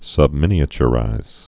(sŭbmĭnē-ə-chə-rīz)